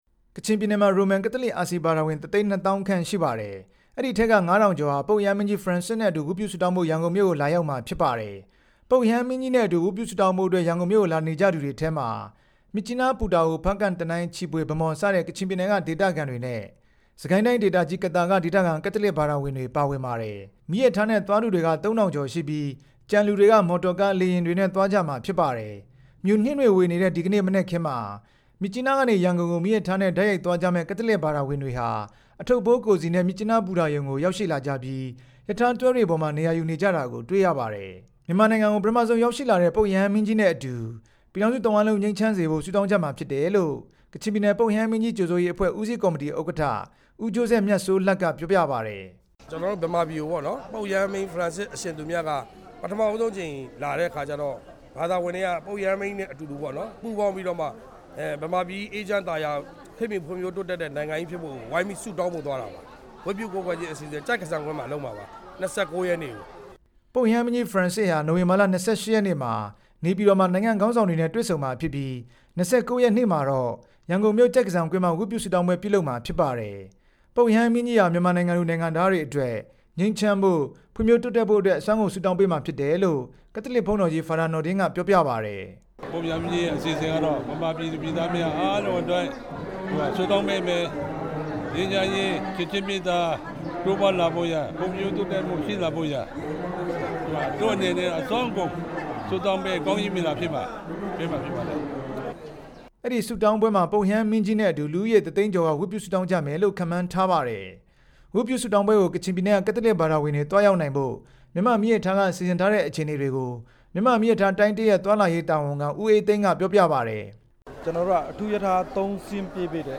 မြစ်ကြီးနားမြို့ကနေ သတင်းပေးပို့တင်ပြထားပါတယ်။